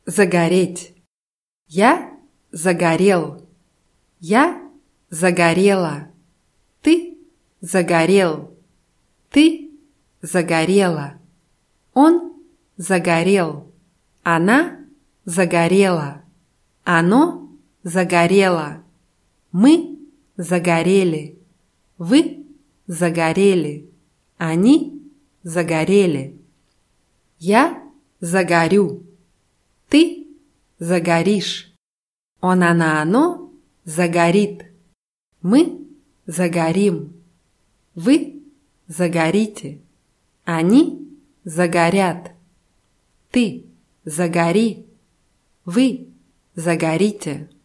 загореть [zagarʲétʲ]